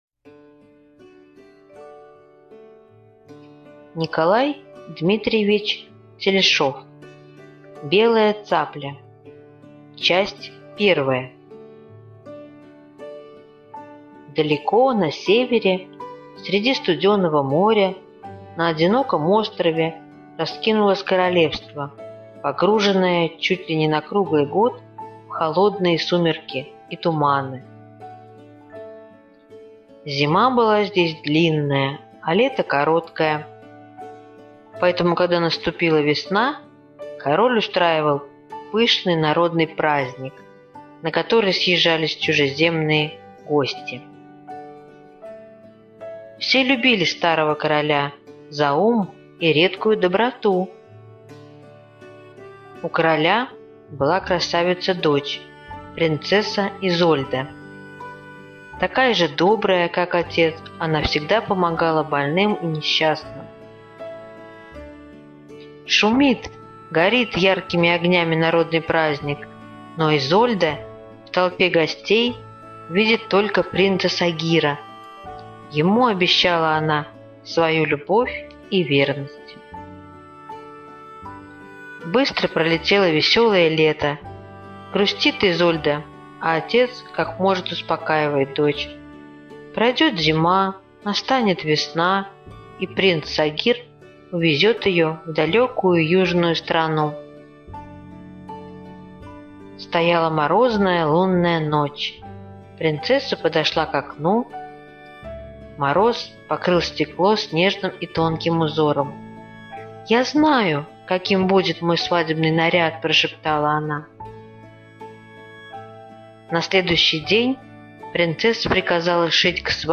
Белая цапля - аудиосказка Телешова Н.Д. Сказка про юную принцессу, которая для свадебного наряда разрешила убить белую цаплю.